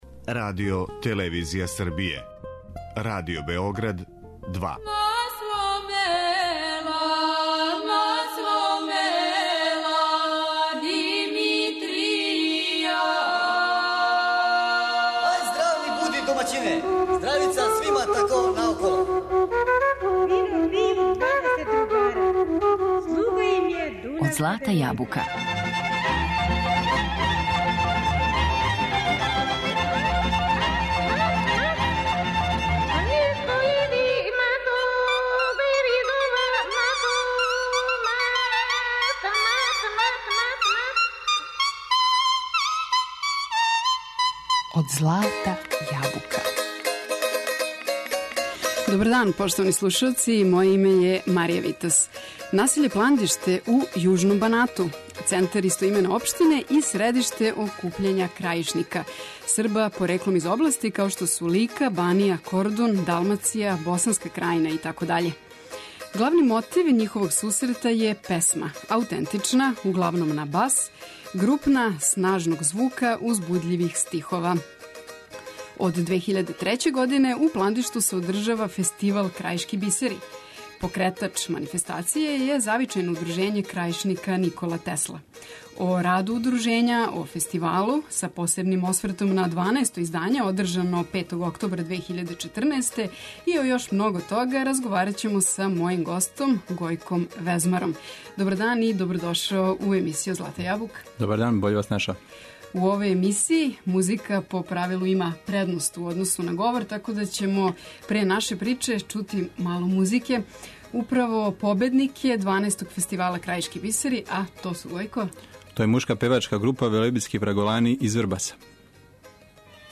Током емисије слушамо свеже снимке крајишке традиције, начињене пре непуне три седмице на фестивалу у Пландишту.